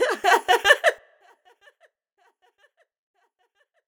Metro New Laugh FX 2.wav